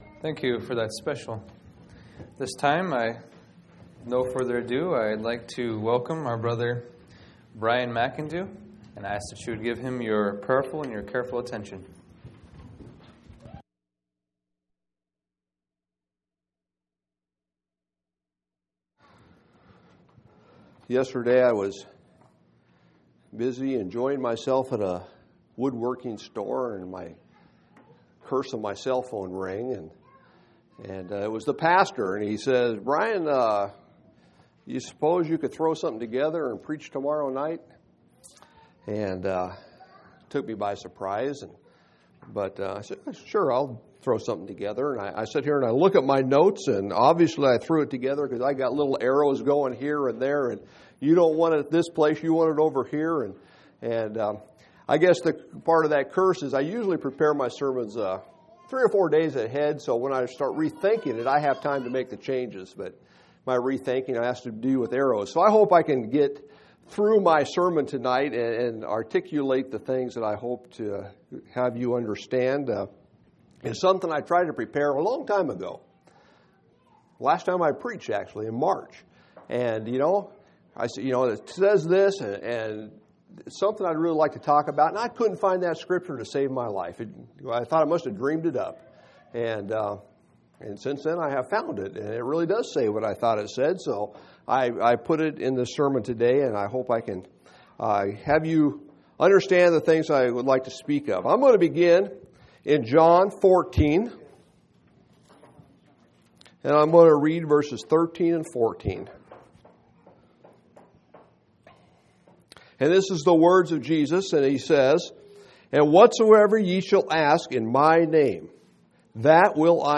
6/19/2005 Location: Phoenix Local Event